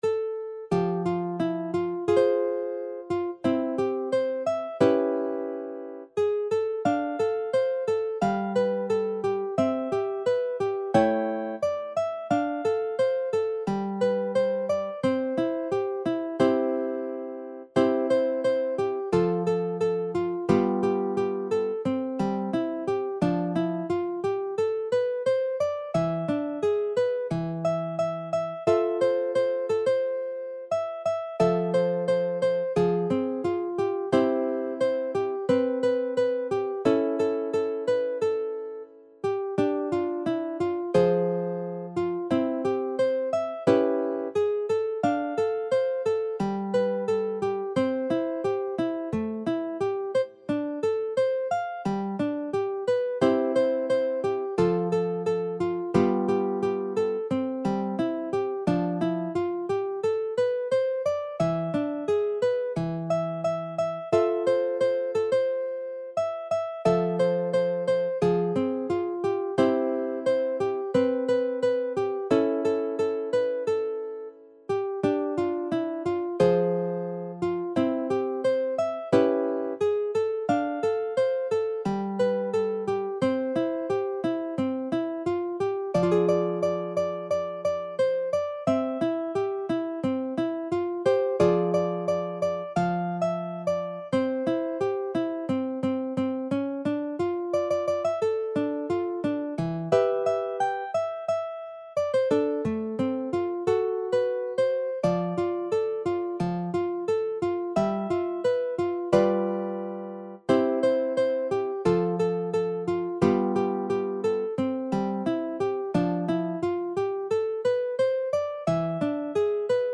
J-POP / フォーク・ニューミュージック
楽譜の音源（デモ演奏）は下記URLよりご確認いただけます。
（この音源はコンピューターによる演奏ですが、実際に人が演奏することで、さらに表現豊かで魅力的なサウンドになります！）